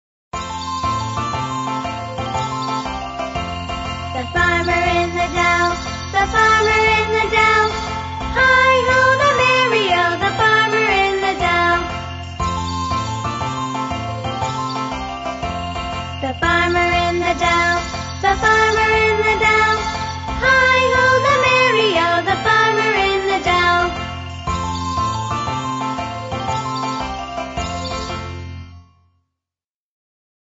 在线英语听力室英语儿歌274首 第218期:The farmer in the dell的听力文件下载,收录了274首发音地道纯正，音乐节奏活泼动人的英文儿歌，从小培养对英语的爱好，为以后萌娃学习更多的英语知识，打下坚实的基础。